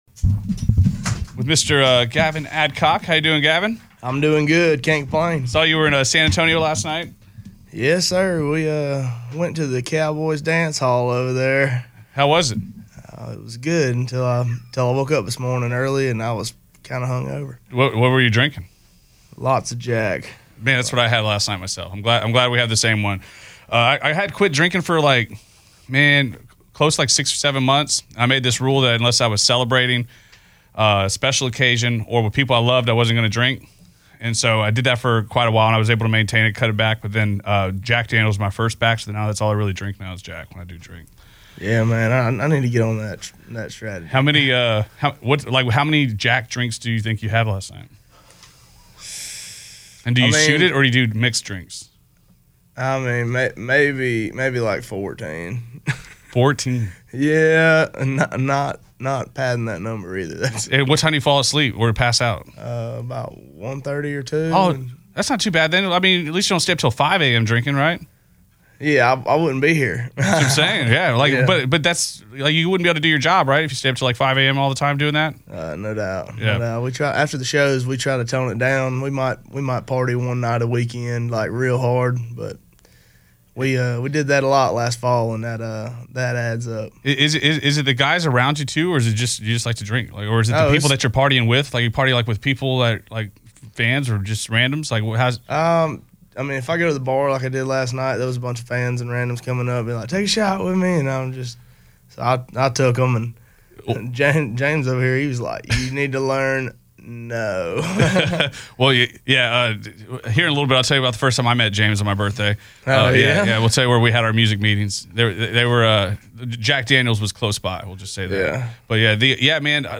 stops by the The Bull Studio at Audacy Houston